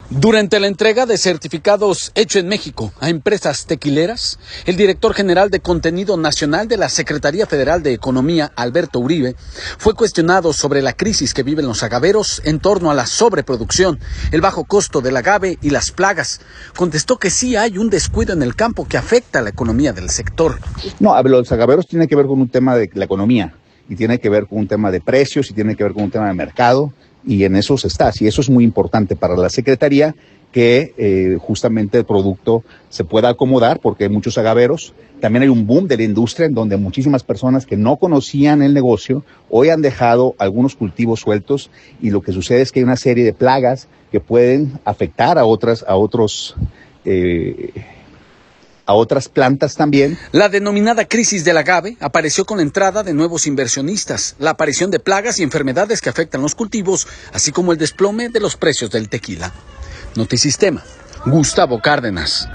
Durante la entrega de certificados ‘Hecho en México’ a empresas tequileras, el director general de contenido nacional de la Secretaría Federal de Economía, Alberto Uribe, fue cuestionado sobre la crisis que viven en los agaveros en torno a la sobreproducción, el bajo costo del agave y plagas. Contestó que sí hay un descuido en el campo que afecta la economía del sector.